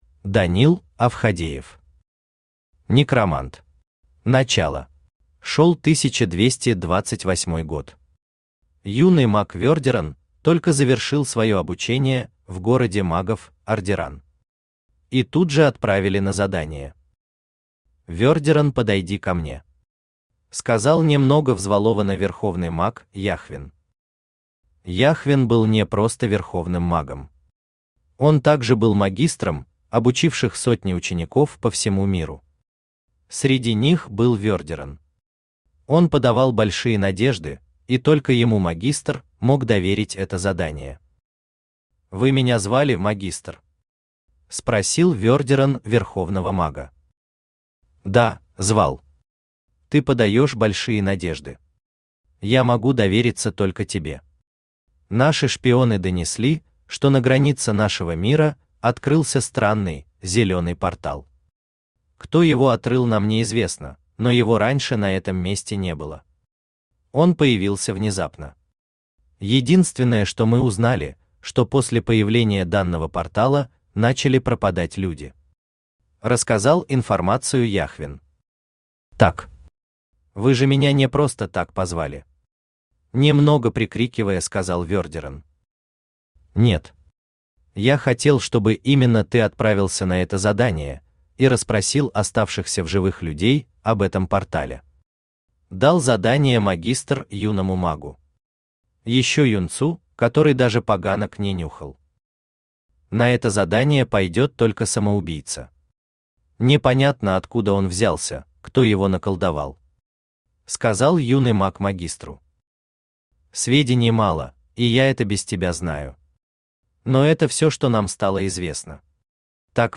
Аудиокнига Некромант. Начало | Библиотека аудиокниг
Читает аудиокнигу Авточтец ЛитРес.